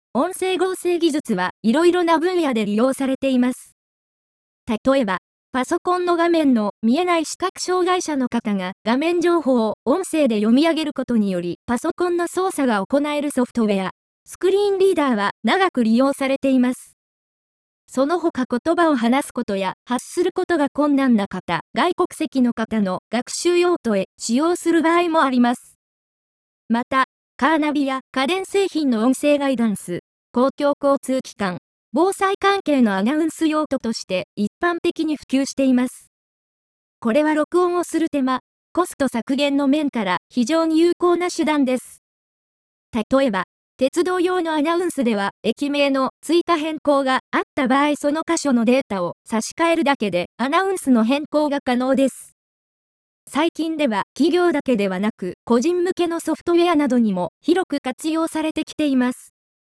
RealNarrators 3はパワーポイント(PowerPoint)を合成音声で読み上げ、スライドショー等の動画用コンテンツを作成するソフトウェアです
超高品質日本語版
ユウカ肉声に迫る自然な発声（女性版）